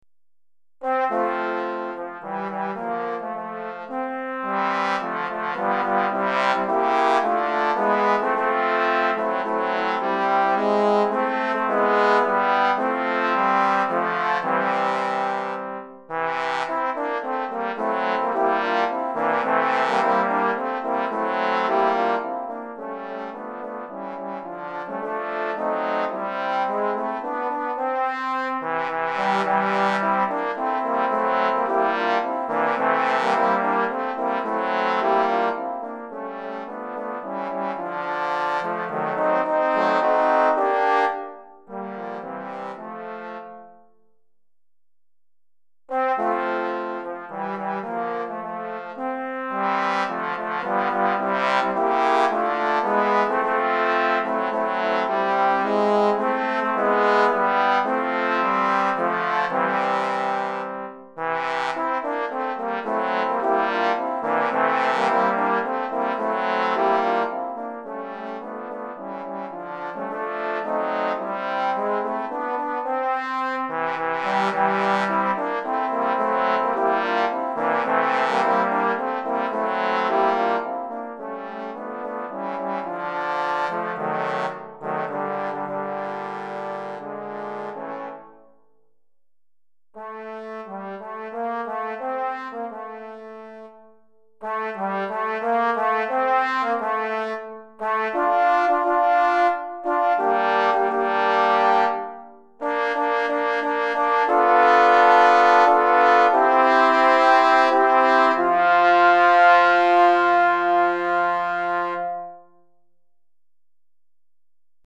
Répertoire pour Trombone - 4 Trombones